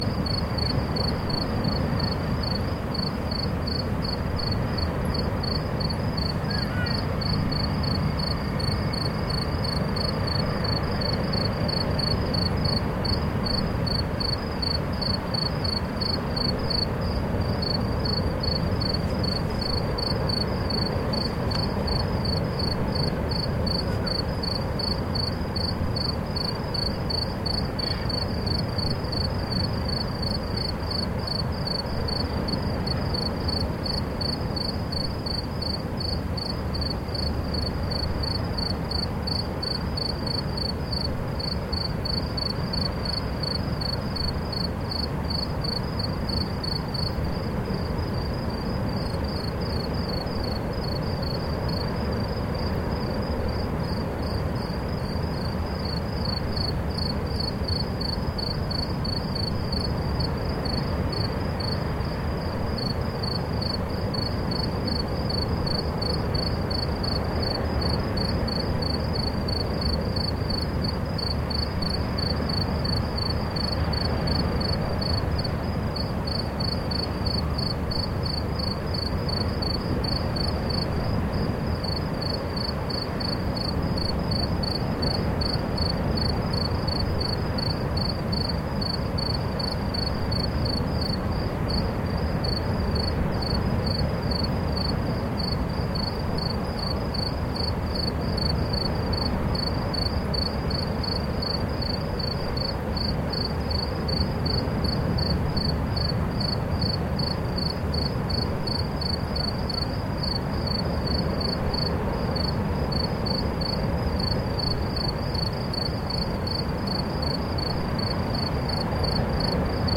At midnight on the island of Stromboli (off Siciliy), crickets and a stormy sea in the distance.